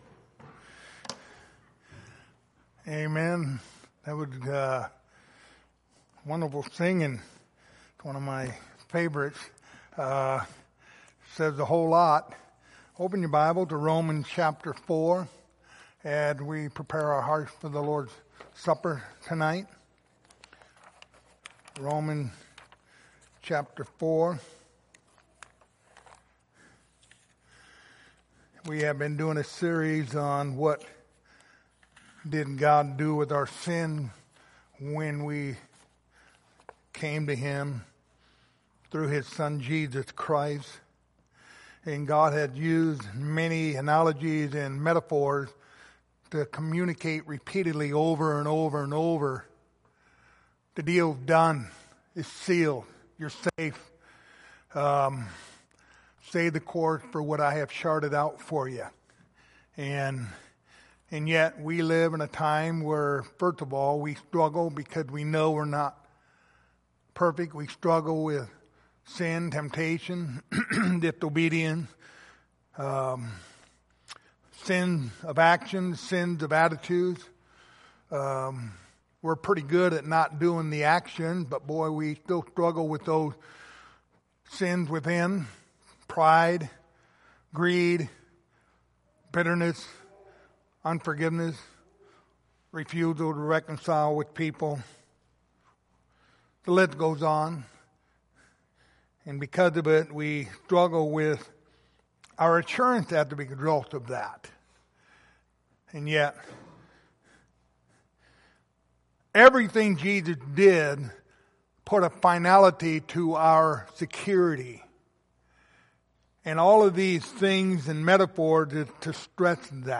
Lord's Supper Passage: Romans 4:7-8 Service Type: Lord's Supper Topics